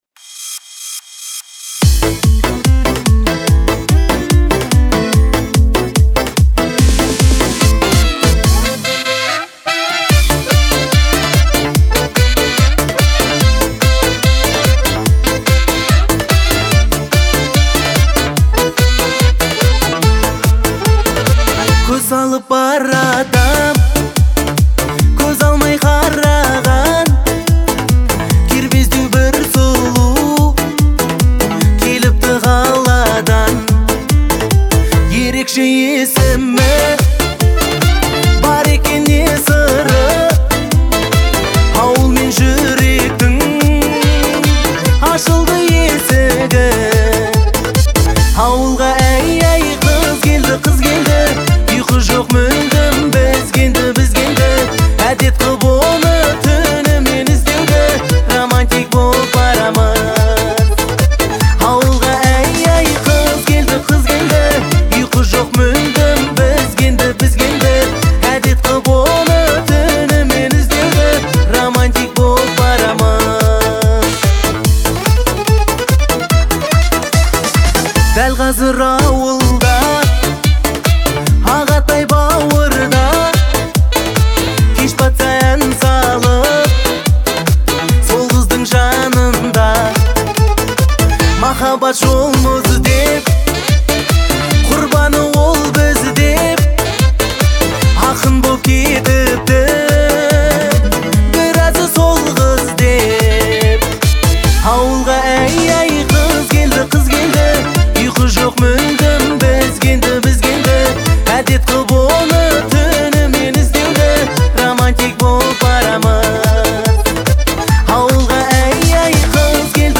это яркий представитель казахского поп-фольклора
отличается мелодичностью и душевностью